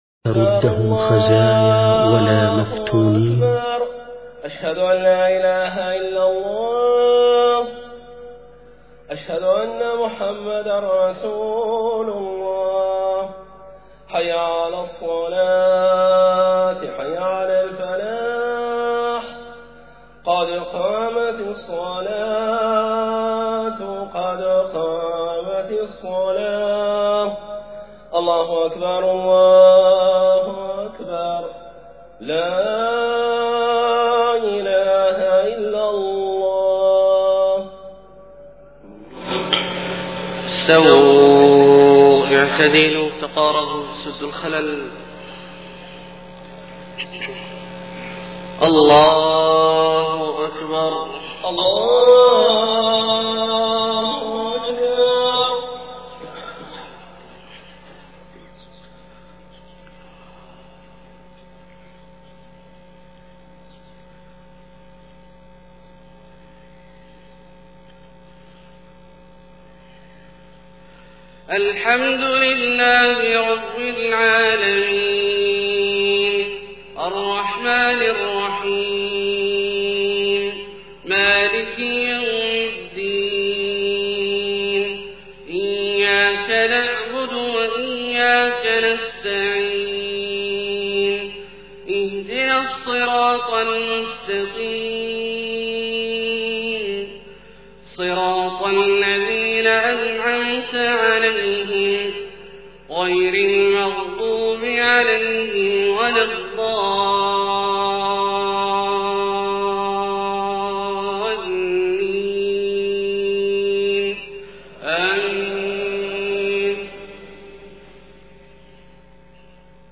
صلاة الفجر 4-4-1430 من سورة طه {55-82} > 1430 🕋 > الفروض - تلاوات الحرمين